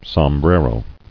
[som·bre·ro]